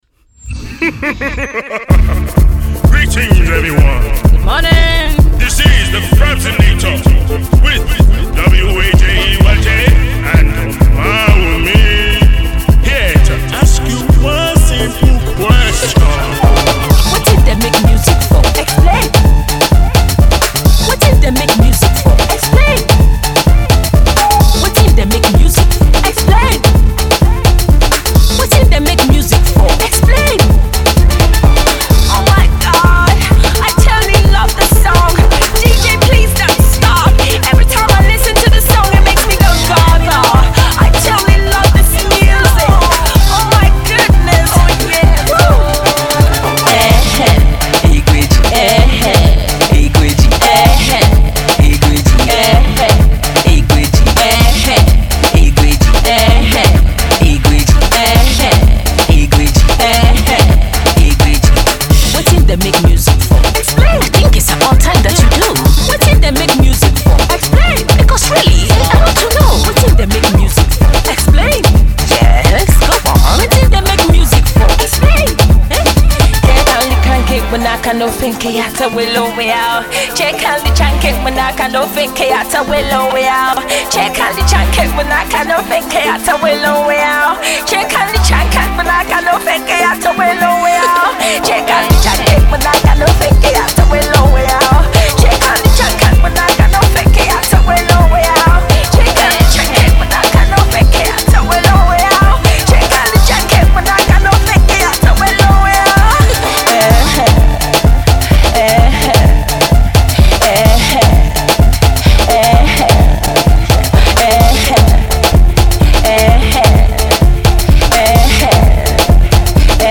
When you put two astoundingly talented singers